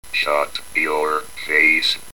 The PIC will supply all 6 phoneme bits and both inflection bits.
sc01-shutyourface.mp3